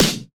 drum-hitclap.wav